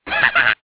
One of Toad's voice clips in Mario Kart DS